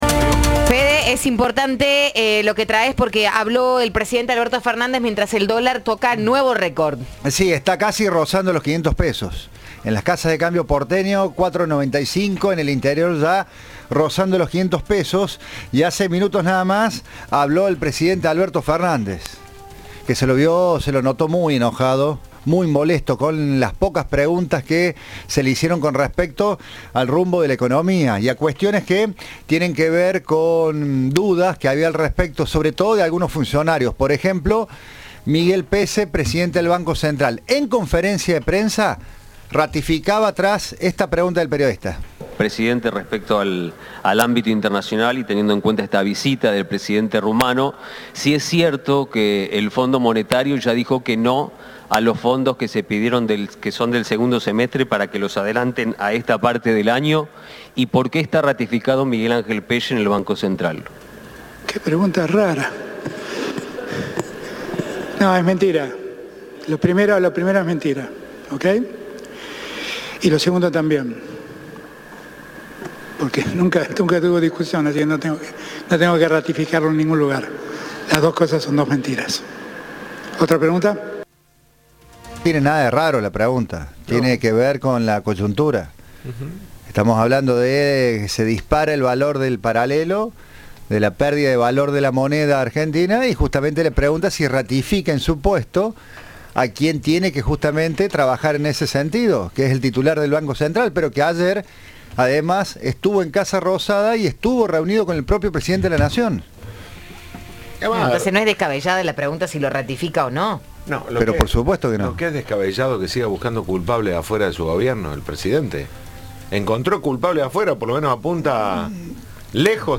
Al brindar una declaración conjunta con el presidente de Rumania, Klaus Iohannis, el jefe de Estado indicó que en el encuentro bilateral hablaron del vínculo entre ambos países e indicó que también dialogaron de las negociaciones para lograr un acuerdo comercial entre la Unión Europea y el Mercosur, así como del impacto de la guerra entre Ucrania y Rusia.